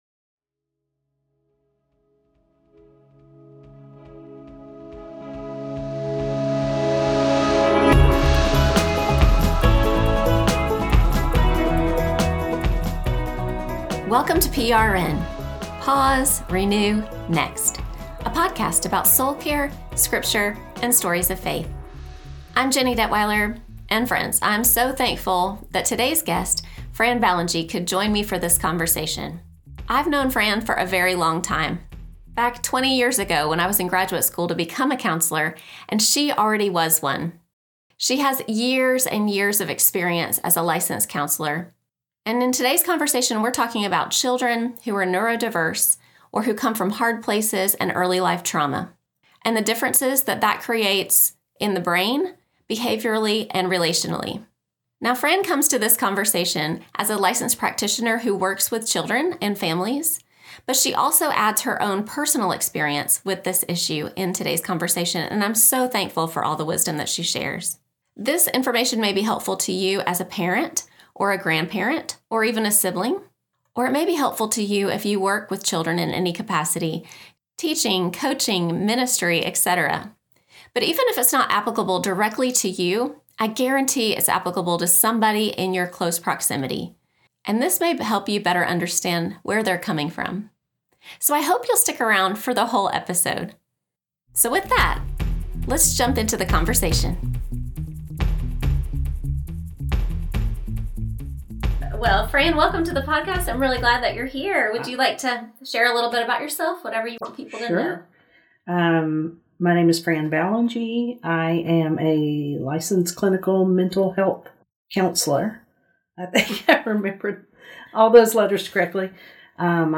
If you know a child with big, baffling behaviors, then today’s episode is for you! If you don’t, stick around, because this conversation will open your eyes to the reasons behind behaviors you may have dismissed as being a result of “willful behavior” or “bad parenting.”